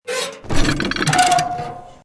CHQ_GOON_rattle_shake.mp3